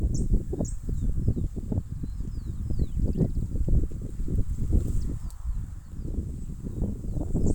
Guaipo (Rhynchotus maculicollis)
Nombre en inglés: Huayco Tinamou
Localización detallada: Ruta 307 Entre El Infiernillo Y Tafi Del Valle
Condición: Silvestre
Certeza: Vocalización Grabada